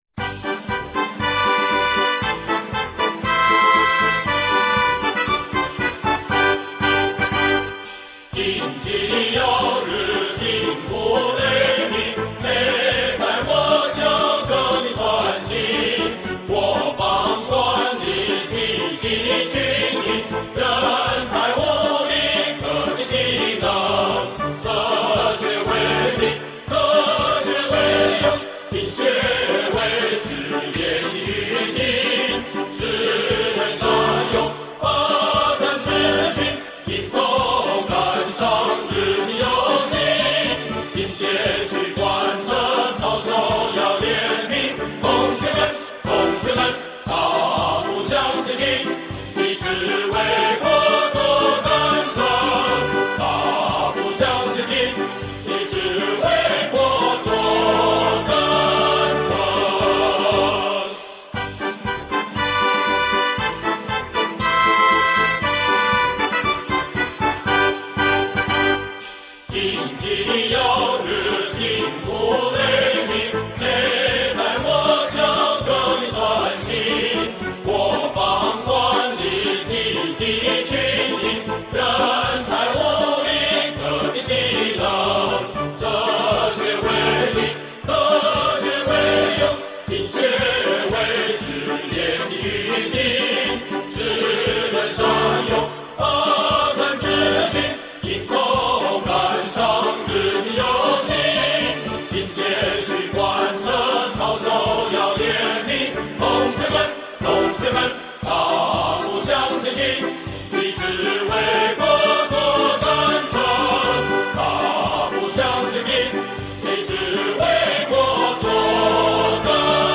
歌唱版RA